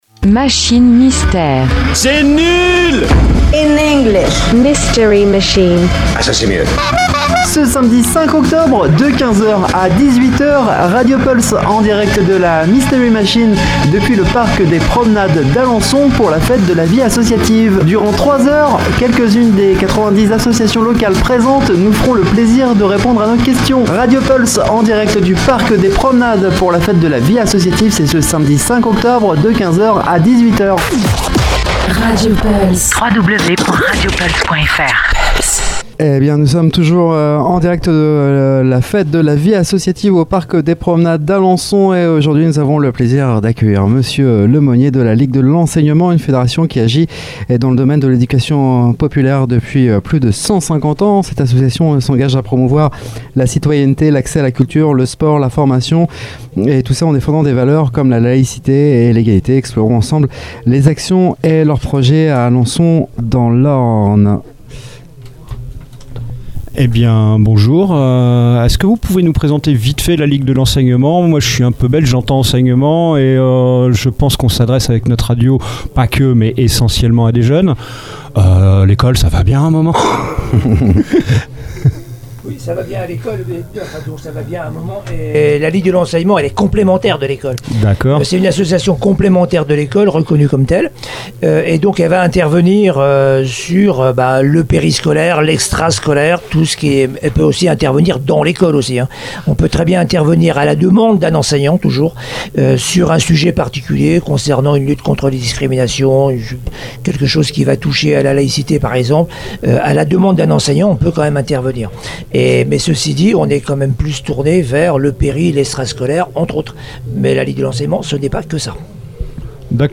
Lors de cette interview